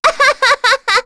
Rehartna-Vox_Happy4.wav